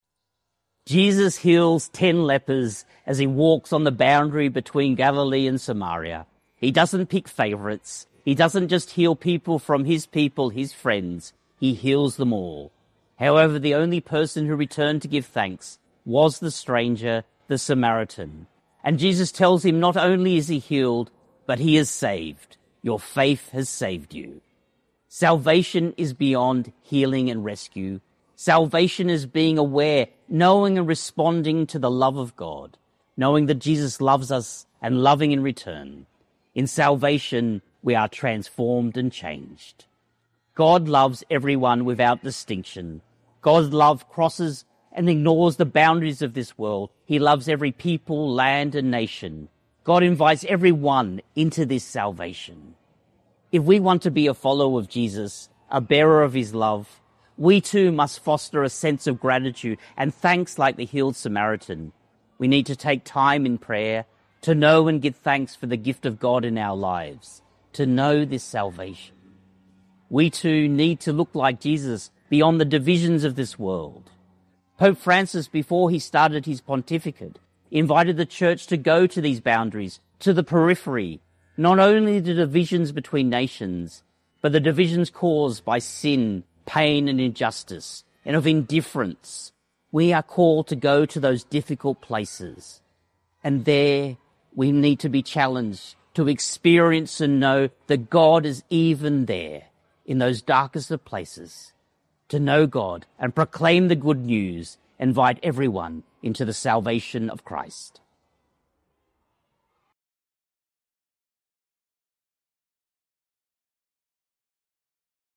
Archdiocese of Brisbane Twenty-Eighth Sunday in Ordinary Time - Two-Minute Homily